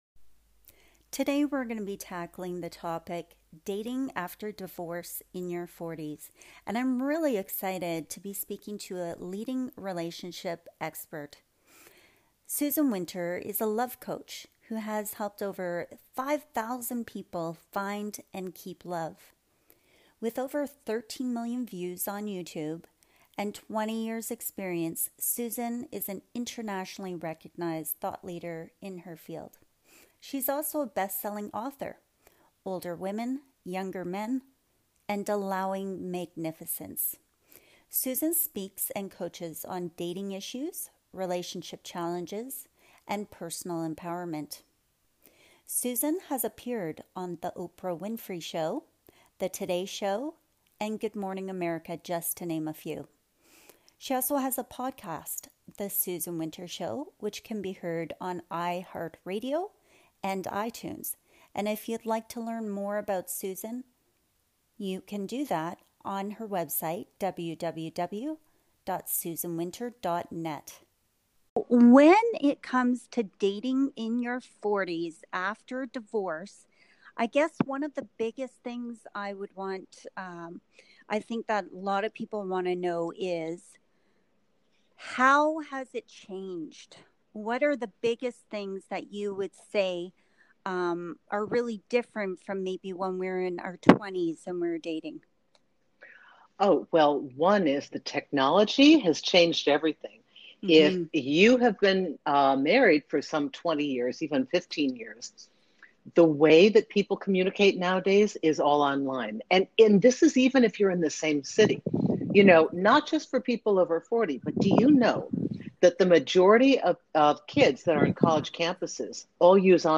Dating after divorce in your 40’s | Alchemy of Women podcast interview